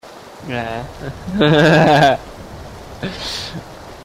Risada mongol